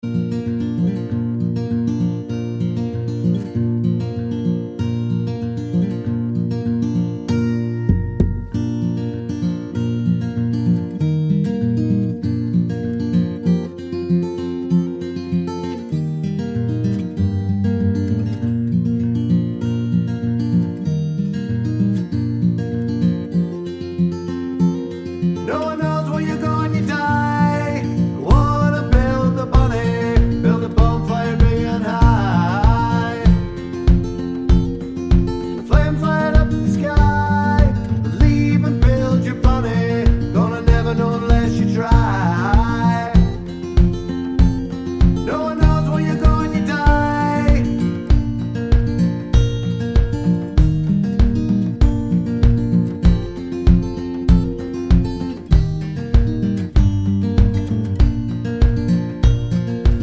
no harmonica Indie / Alternative 2:47 Buy £1.50